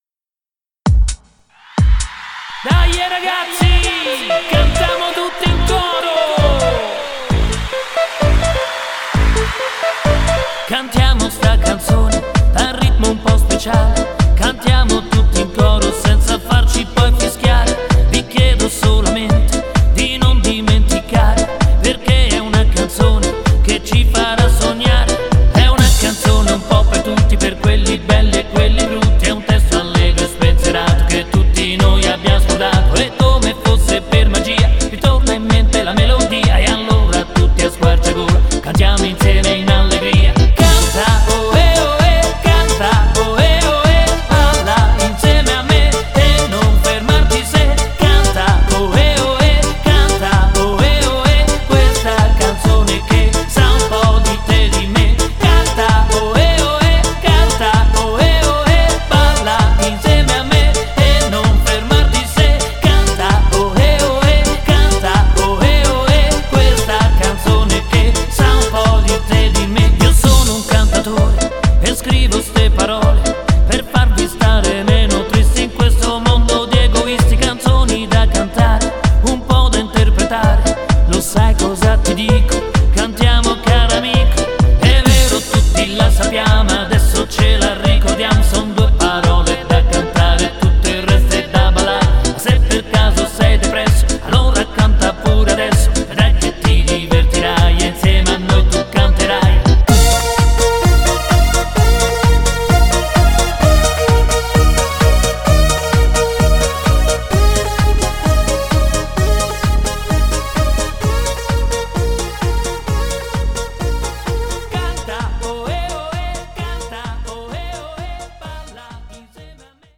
Tiburon